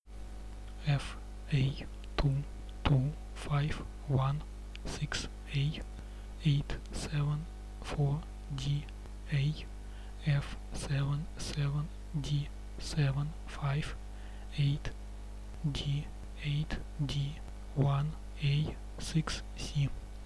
В нём человек произносит 27 шестнадцатеричных цифр: fa22516a874daf77d758d8d1a6c.
Если прислушаться, то на записи иногда слышны щелчки, которые не похожи на некачественную запись с микрофона.
Помечаем такие скачки маркерами, всего их оказывается ровно 5, как раз столько символов не хватало исходному паролю до 32-х. Эти куски записи просто были неаккуратно удалены, что позволило с лёгкостью определить их местоположение.
• Произношение говорящего на записи оставляет желать лучшего, не ясно, произносит ли он букву A или E. Таких букв всего 4, что увеличивает пространство паролей (и соответственно время перебора) в 16 раз.